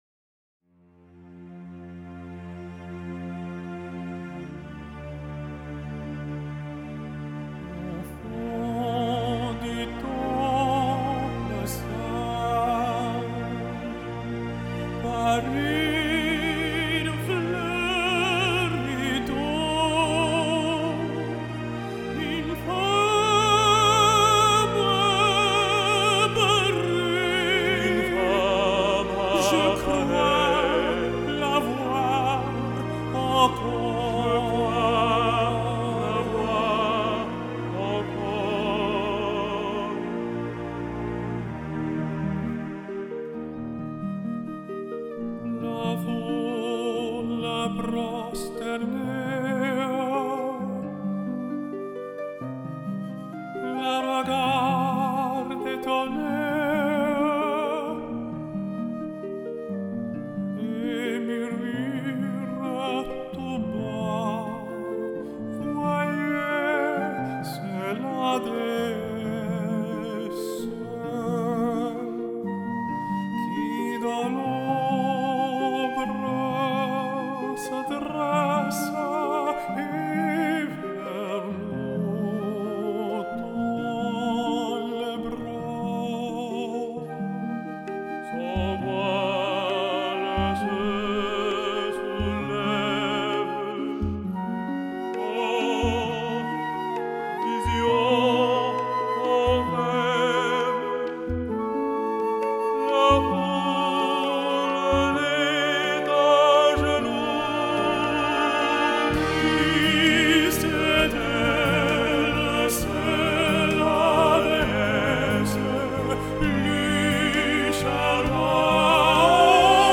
featuring Vancouver tenor